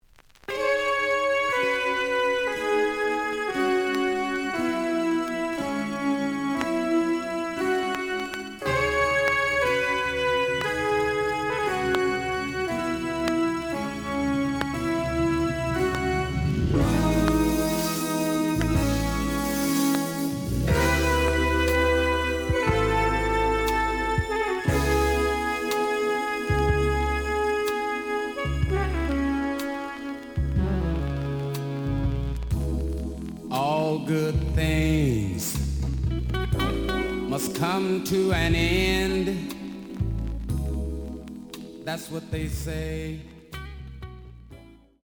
The audio sample is recorded from the actual item.
●Genre: Funk, 70's Funk
Some click noise on beginning of B side due to scratches.